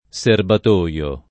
serbatoio [ S erbat 1L o ] s. m.; pl. -toi